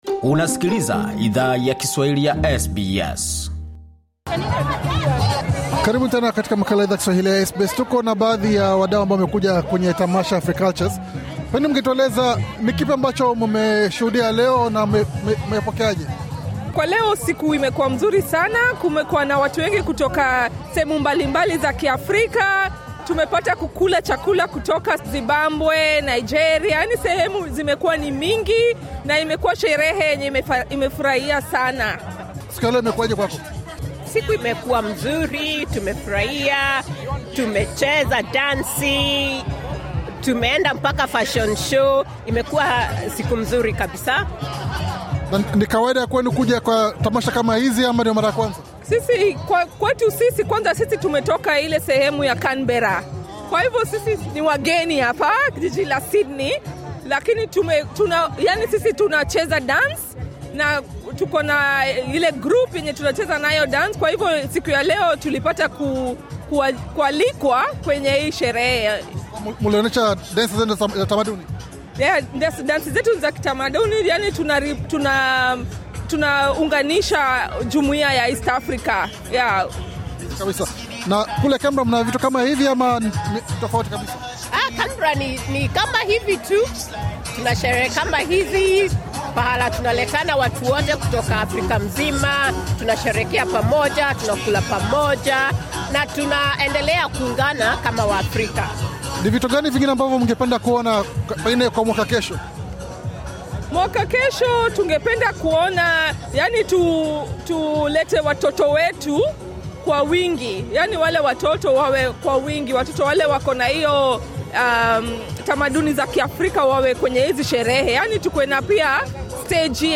Katika mazungumzo maalum na SBS Swahili, wamiliki wa mgahawa huo wali funguka kuhusu safari yao katika biashara yaku uza vyakula pamoja na mapokezi ya vyakula vyao kwenye tamasha hiyo. Wateja wao nao pia wali changia hisia zao kuhusu vyakula walivyo nunua kwenye mgahawa huo.